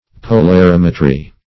Meaning of polarimetry. polarimetry synonyms, pronunciation, spelling and more from Free Dictionary.
Search Result for " polarimetry" : The Collaborative International Dictionary of English v.0.48: Polarimetry \Po`lar*im"e*try\, n. (Opt.) The art or process of measuring the polarization of light.